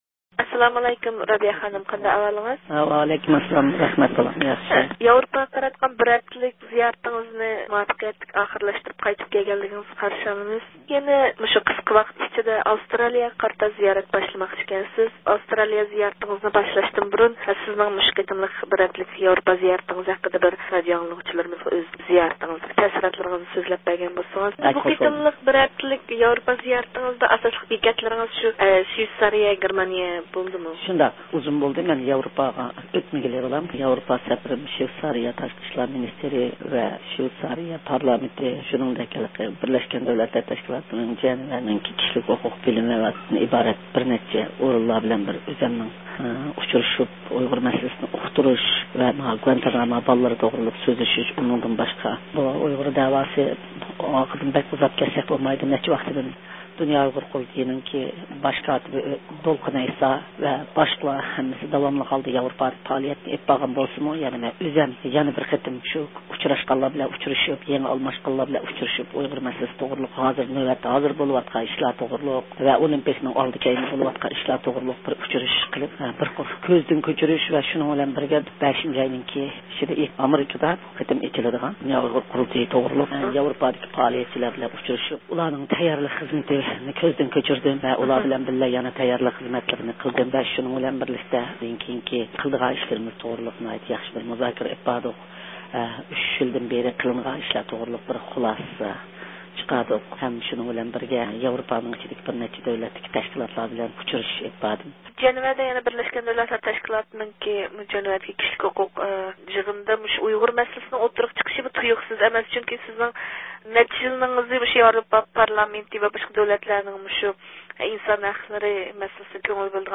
رابىيە خانىم يەنە بىر ئىككى كۈن ئىچىدە ئاۋسترالىيىگە قارىتا زىيارەت قەدىمىنى ئېلىشقا تەييارلىق قىلماقتا ، بىز رابىيە قادىر خانىمنىڭ بۇ قېتىم ياۋروپادىكى شۋېيتسارىيە ۋە گېرمانىيىلەردە ئېلىپ بارغان مۇھىم زىيارەت پائالىيەتلىرى ھەمدە ئۇنىڭ زىيارەت تەسىراتلىرىنى ئېلىش ئۈچۈن رابىيە قادىر خانىم بىلەن تېلېفون زىيارىتى ئۆتكۈزدۇق.